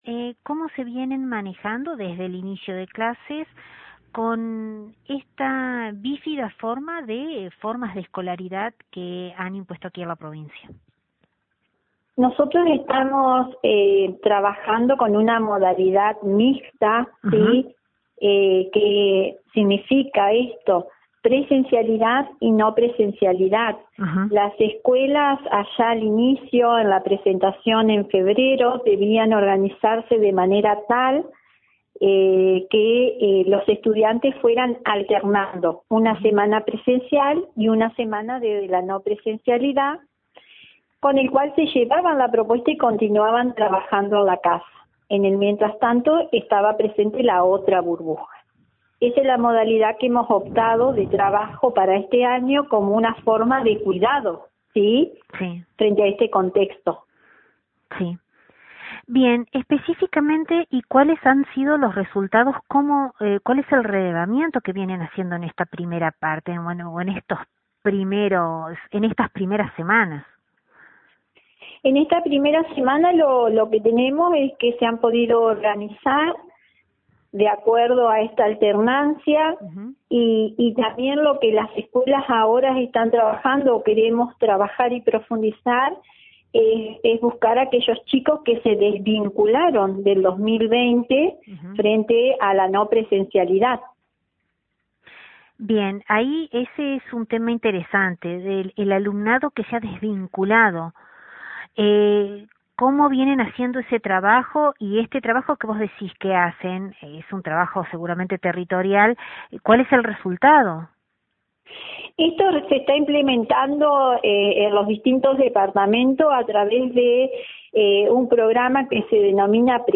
Sobre la modalidad mixta de escolaridad hablamos con la Directora de Nivel Secundario de la provincia